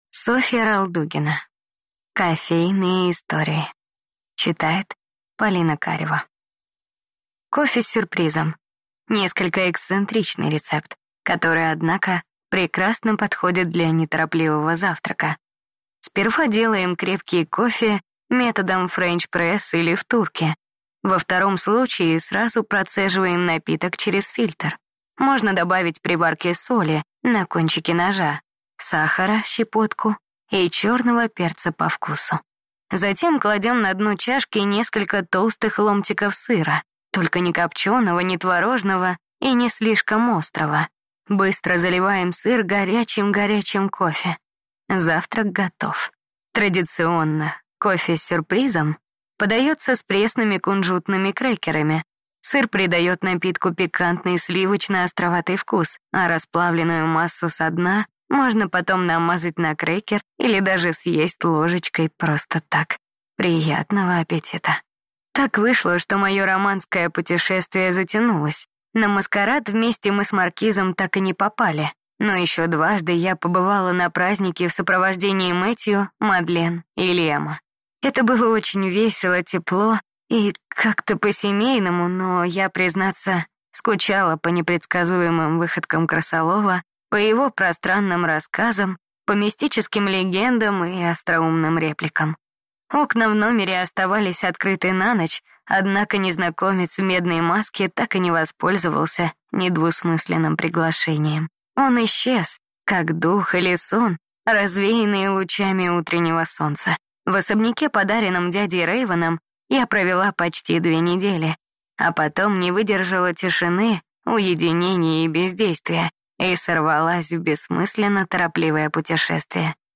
Аудиокнига Кофе с сюрпризом | Библиотека аудиокниг
Прослушать и бесплатно скачать фрагмент аудиокниги